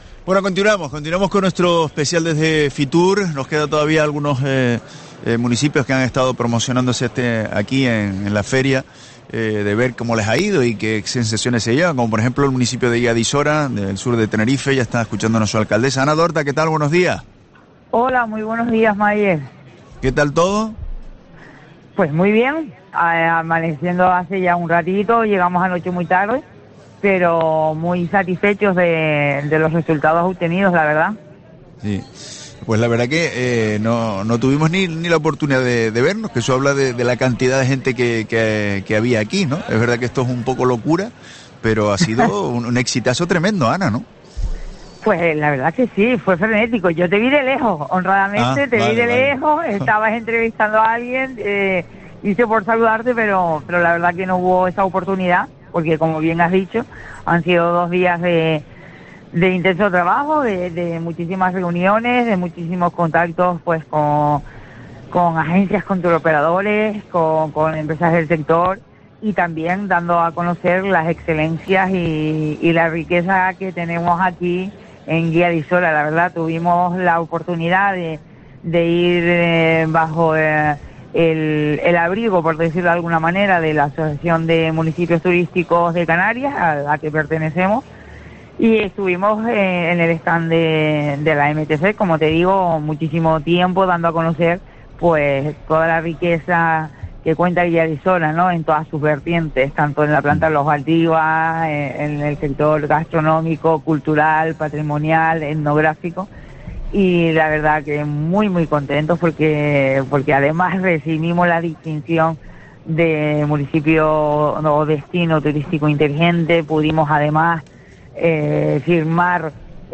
Entrevista a Ana Dorta, alcaldesa de Guía de Isora, en FITUR 2024